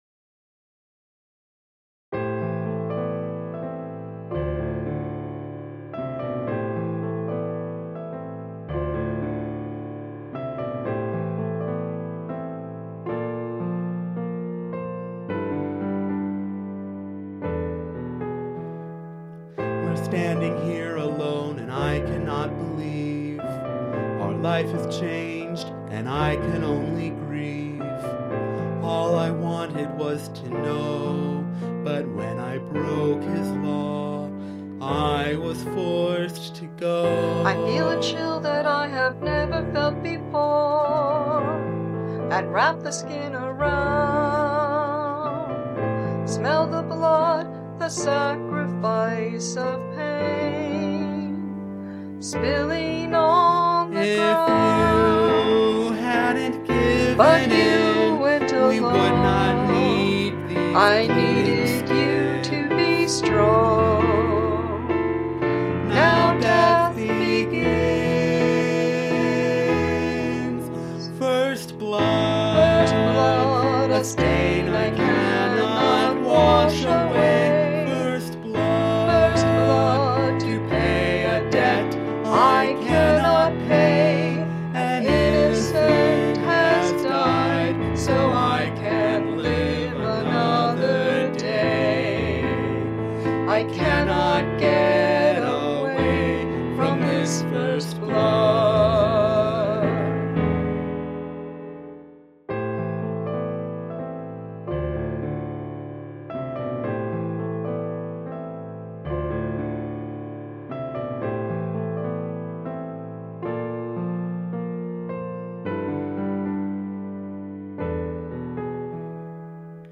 It's quite pretty.